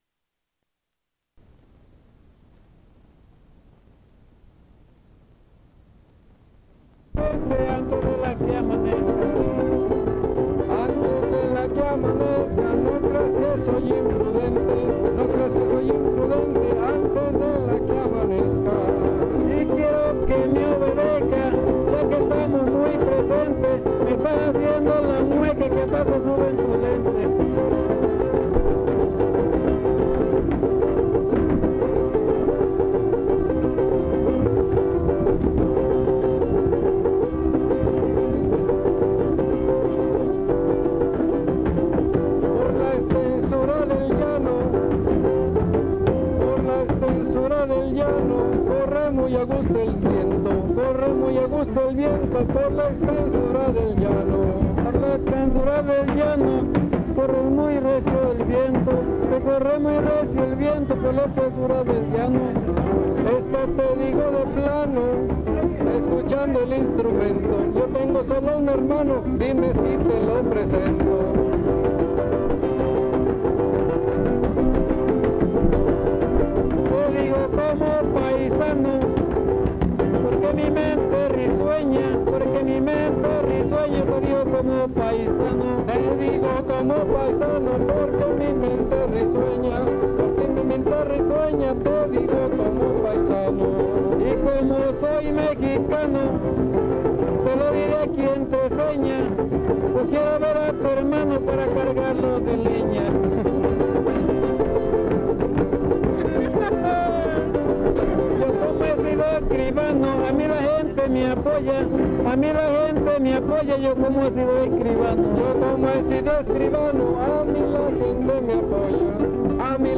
Santiago Tuxtla, Veracruz
Versos improvisados Poesía popular
Concurso Estatal de Fandango